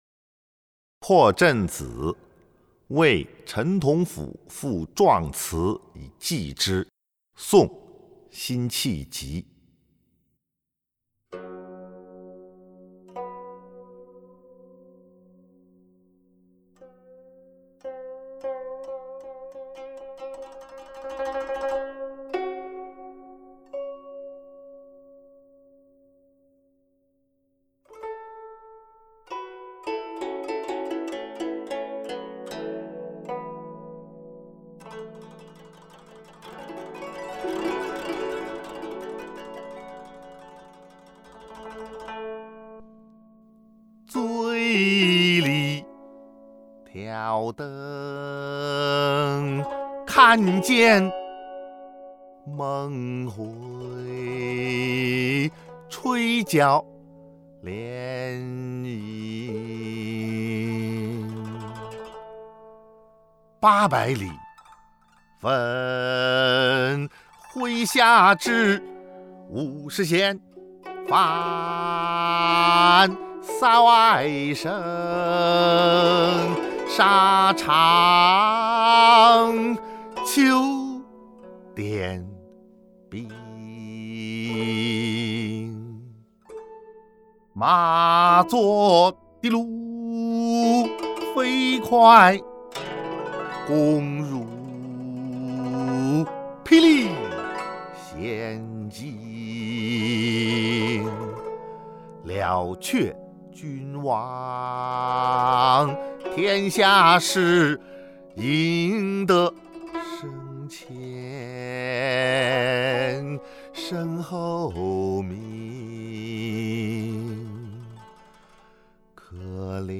［宋］辛弃疾《破阵子·为陈同甫赋壮词以寄之》（吟咏）